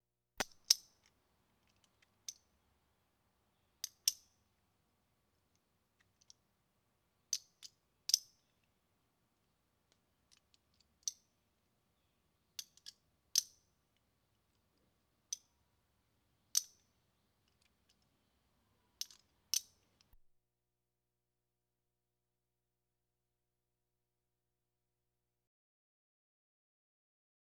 transport
Car Seat Belt Safety Harness Clip And Unclip 1